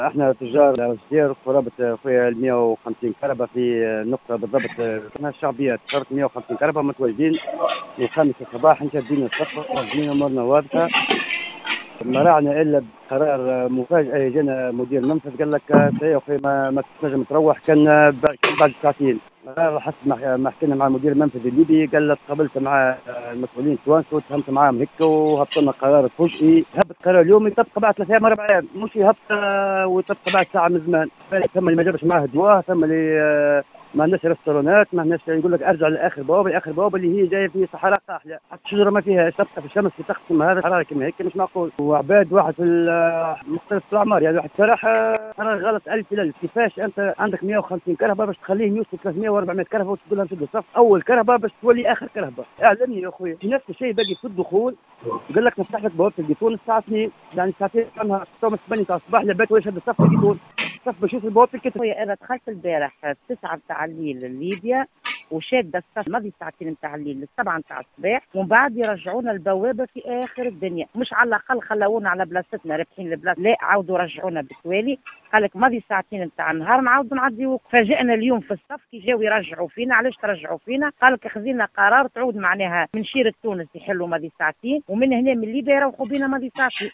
tojar-ben-guerdane.mp3